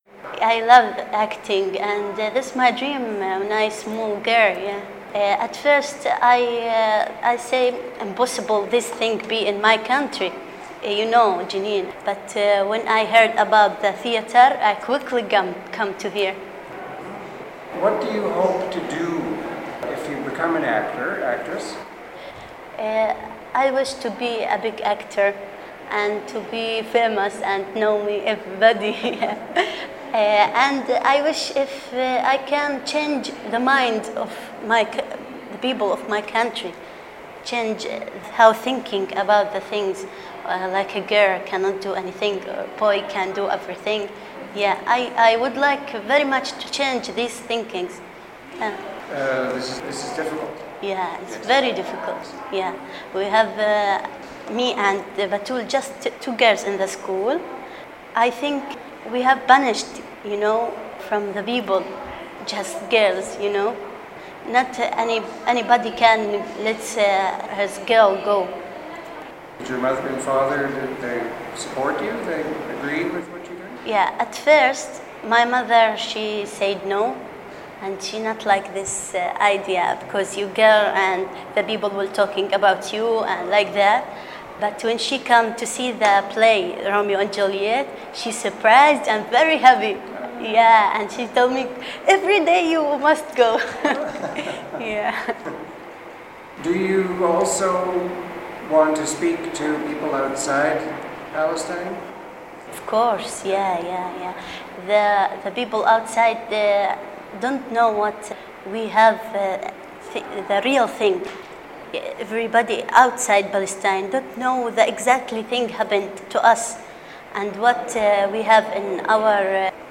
In the few minutes left before class resumes, a young woman in a rumpled orange tracksuit agrees to a quick interview. We sit on the stone balcony, a light breeze soft and pleasant on the face.